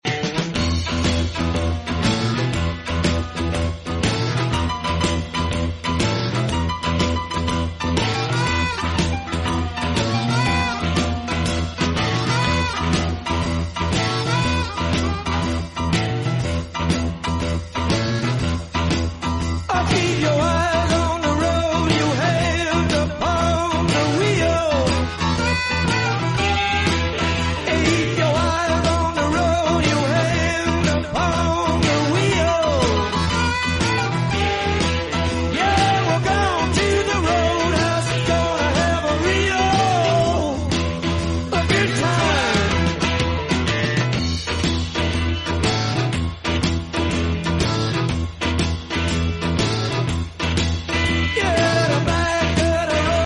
Upload By Blues, rock and harmonica